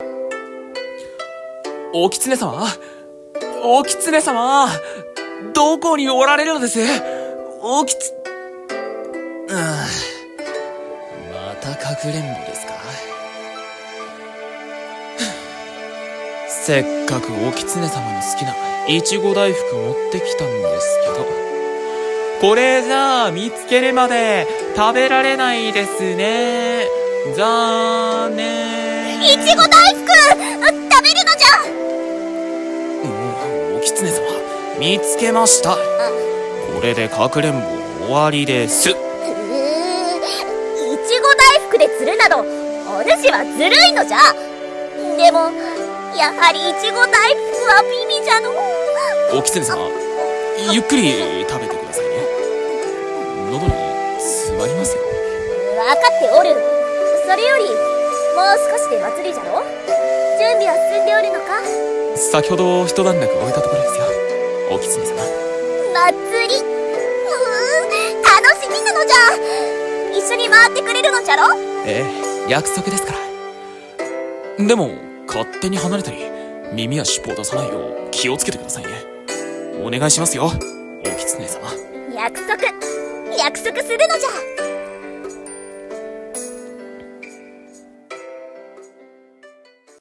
【声劇】お狐様とお祭りでの約束事【掛け合い】